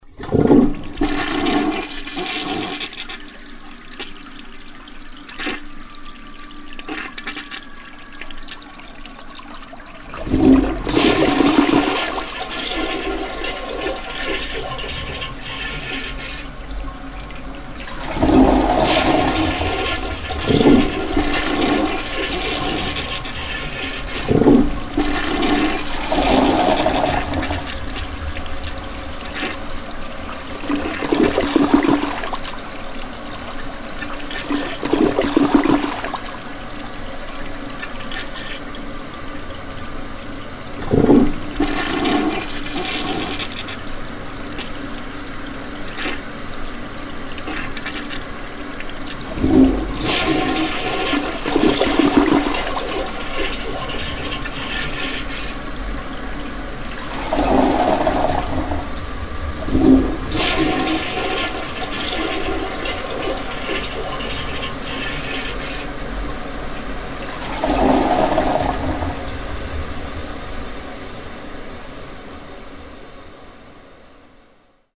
Toilet Sound